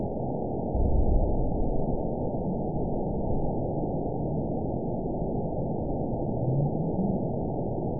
event 914100 date 04/27/22 time 21:38:58 GMT (3 years ago) score 9.29 location TSS-AB01 detected by nrw target species NRW annotations +NRW Spectrogram: Frequency (kHz) vs. Time (s) audio not available .wav